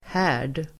Uttal: [h'ä:r_d]